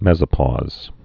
(mĕzə-pôz, mĕs-)